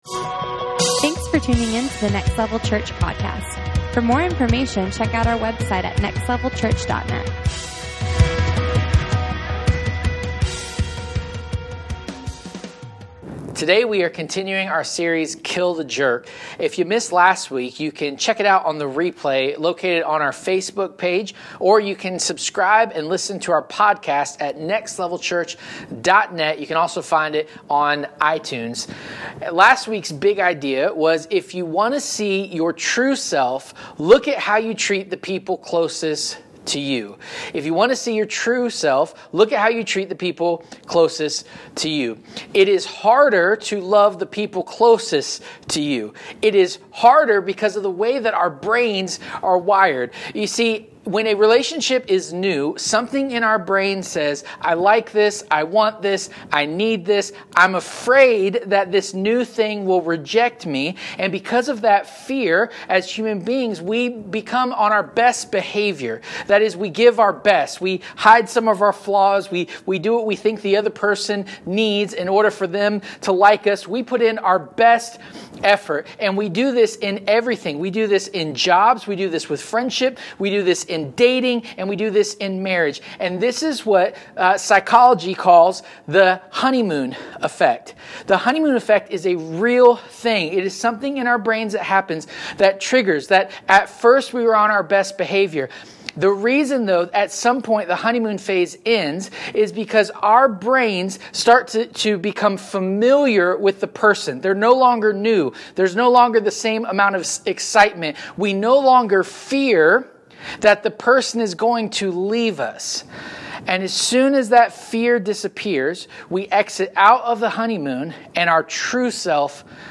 Kill The Jerk Service Type: Sunday Morning Watch We all know a jerk.